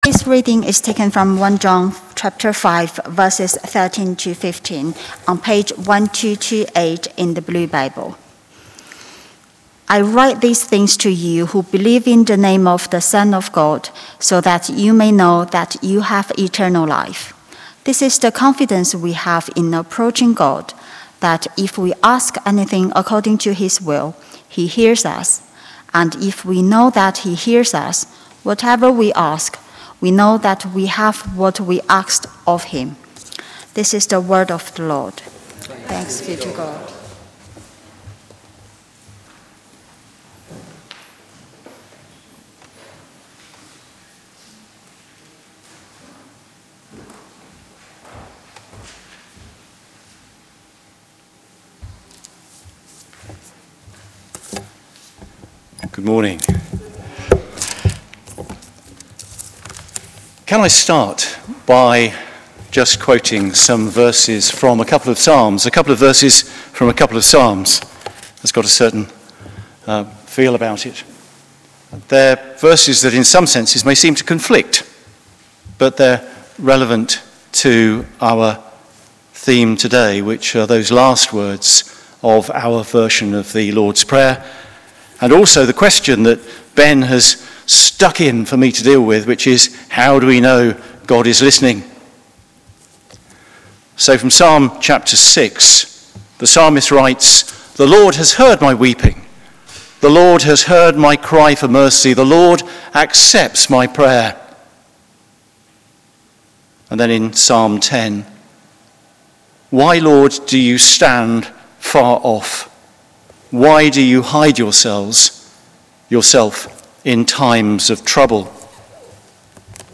Listen to our 9.30am and 11.15am sermon here: